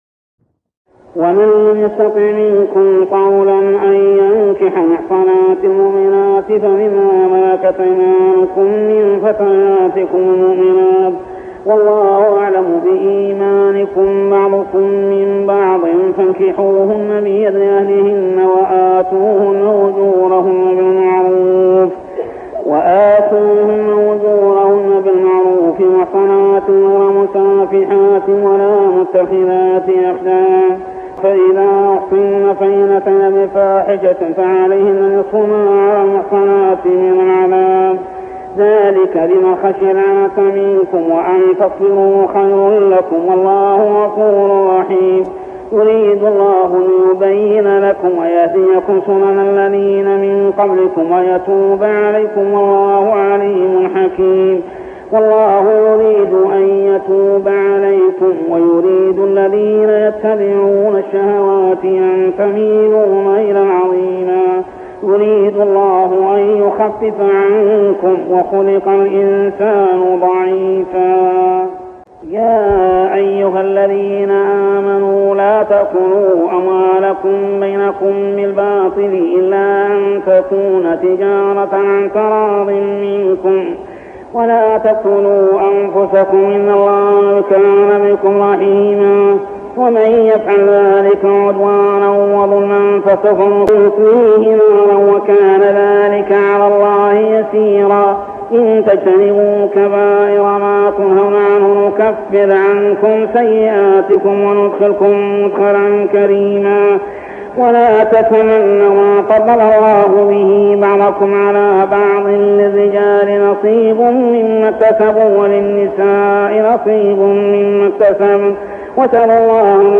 صلاة التراويح عام 1403هـ سورة النساء 25-86 | Tarawih Prayer Surah An-Nisa > تراويح الحرم المكي عام 1403 🕋 > التراويح - تلاوات الحرمين